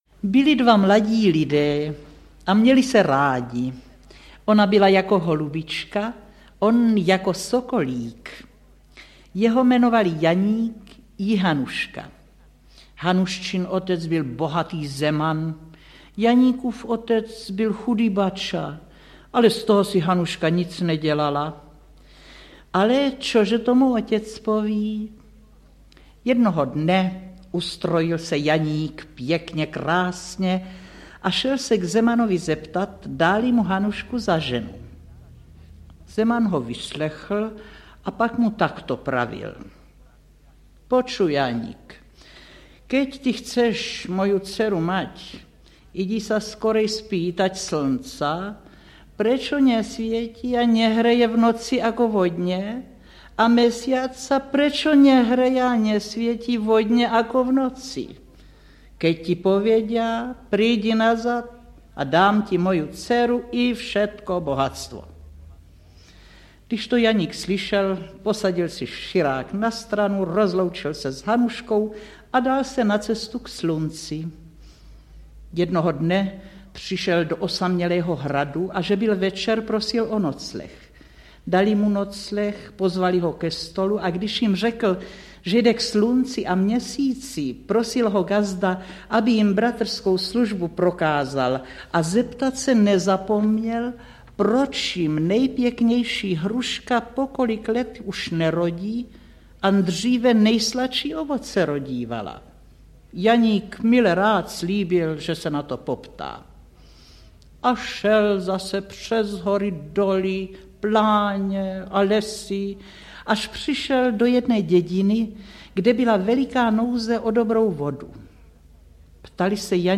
Album pohádek "Supraphon dětem" 19 audiokniha
Ukázka z knihy
• InterpretStella Zázvorková, Vlastimil Brodský, Jan Přeučil, Štěpánka Haničincová, Růžena Nasková, Otýlie Beníšková